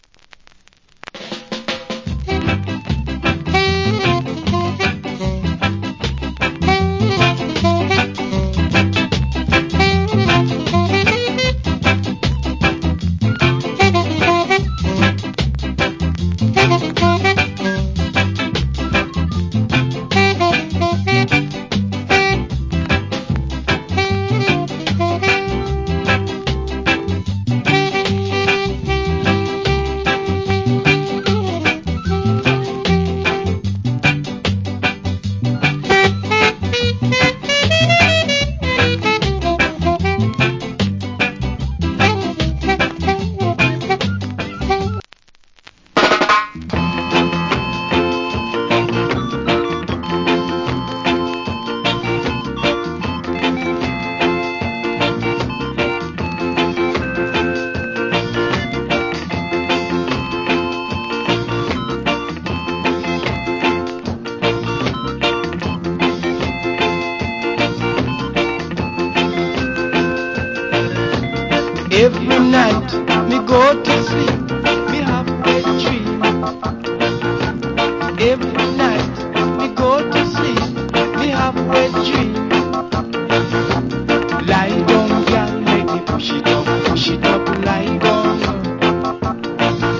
Wicked Inst.